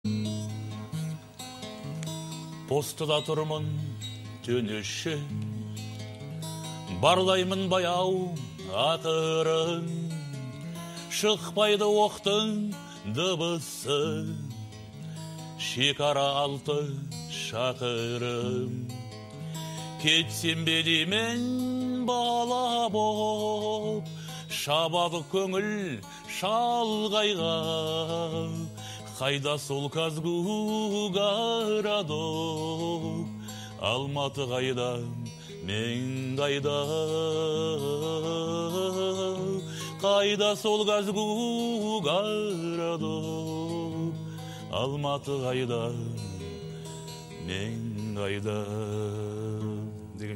это песня в жанре казахского поп-фолка